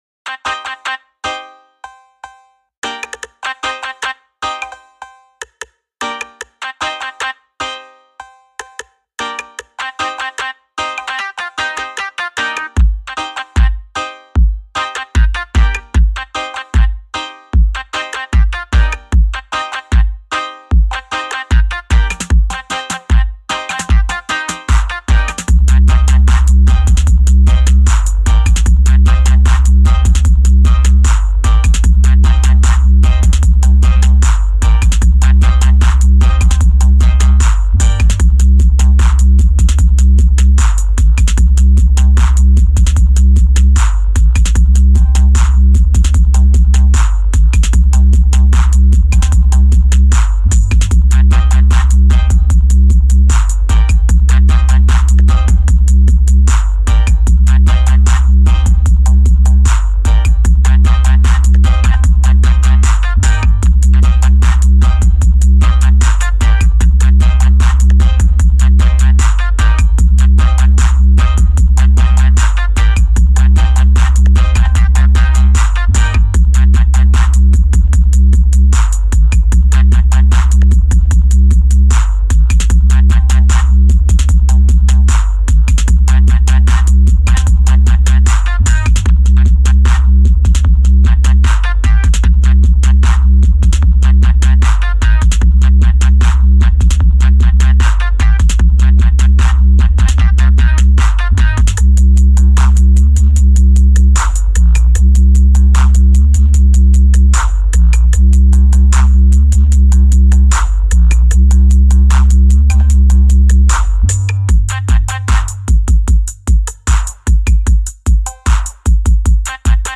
early mix with added SSS4 filter.